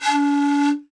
Index of /90_sSampleCDs/Roland LCDP11 Africa VOL-1/WND_Afro Pipes/WND_Afro Pipes